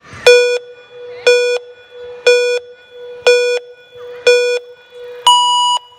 Звуки гонок
7. Финальный стандартный звук старта гонок